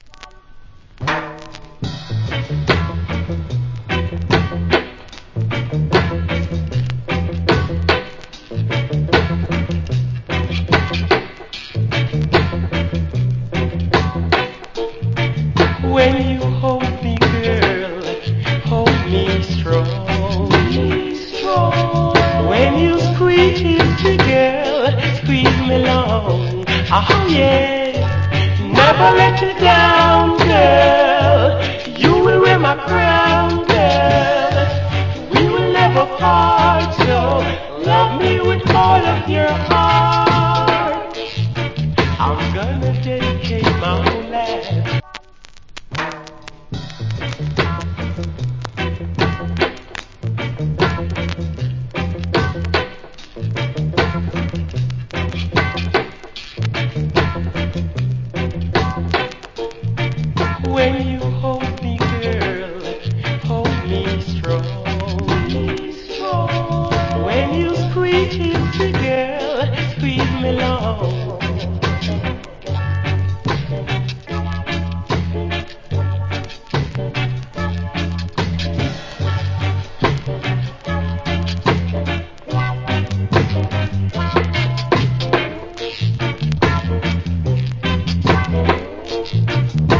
Cool Rock Steady.